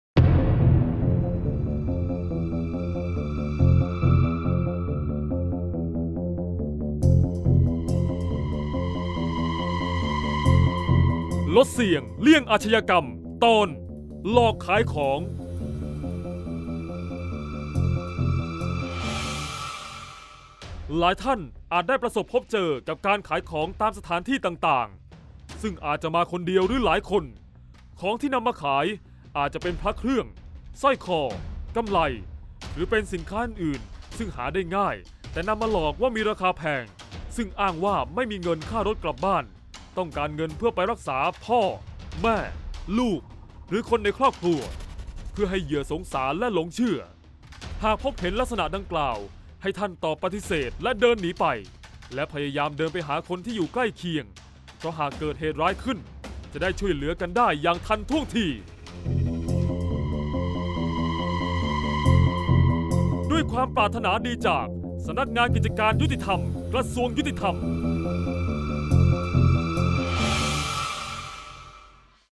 เสียงบรรยาย ลดเสี่ยงเลี่ยงอาชญากรรม 50-หลอกขายของ